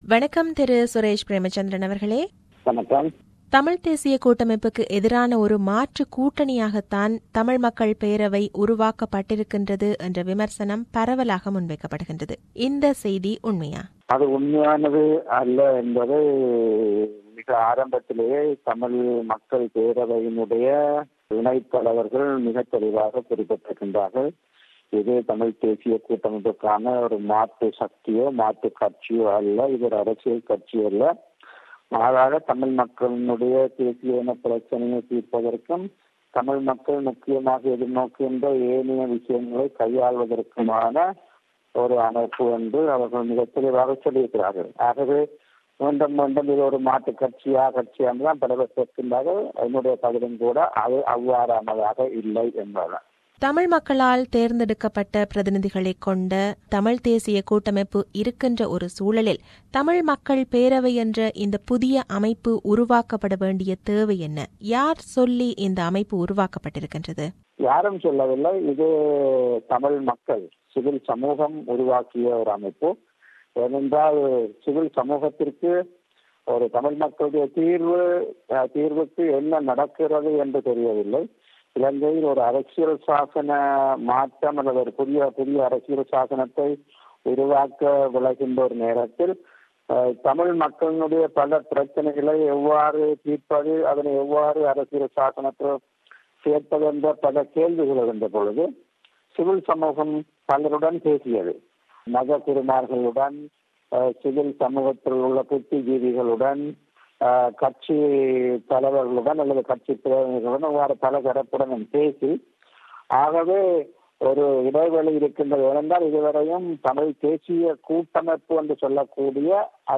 This is an interview with Mr. Suresh Premachandran.